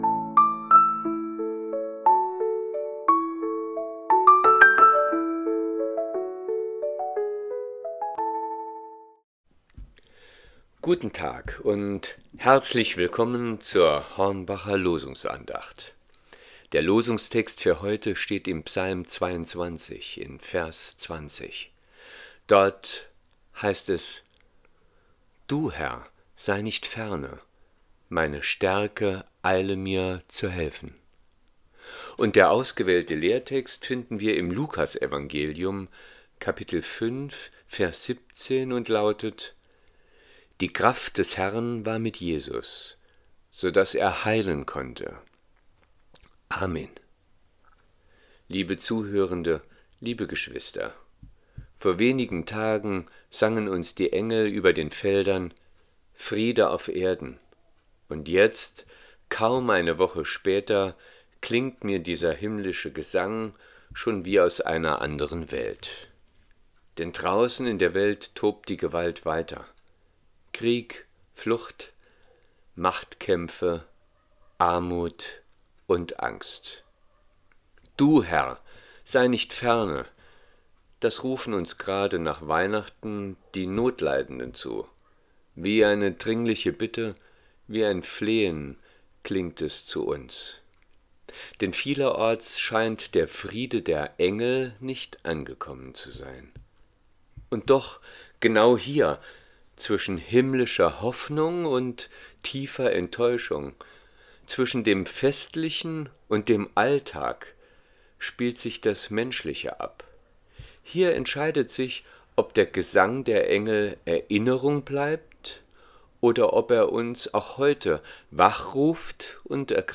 Losungsandacht für Montag, 29.12.2025